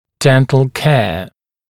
[‘dent(ə)l keə][‘дэнт(э)л кэа]уход за полостью рта, стоматологическое лечение